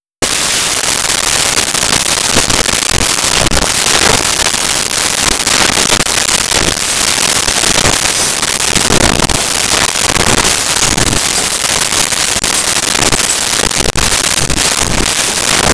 Flatware Movement
Flatware Movement.wav